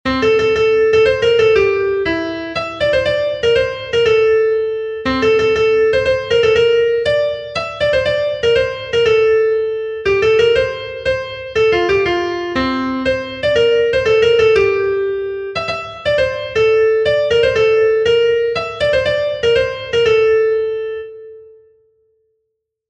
Sentimenduzkoa
Zortziko handia (hg) / Lau puntuko handia (ip)
A-A2-B-C